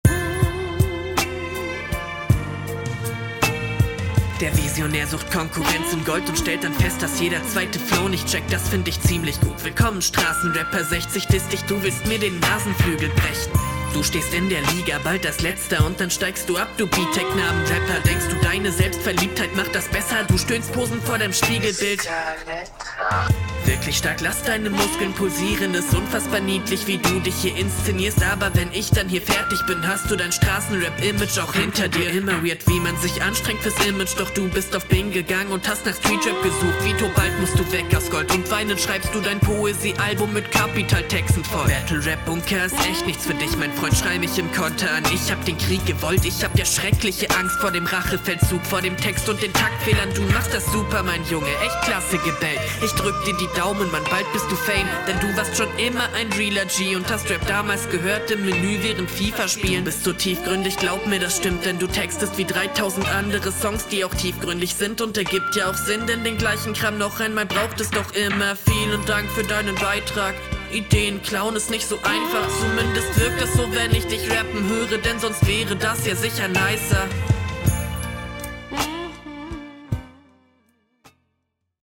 ich werde jeden tyler the creator type beat produzent steinigen
3/4 beat aber 4/4 toll